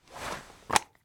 pistol_holster.ogg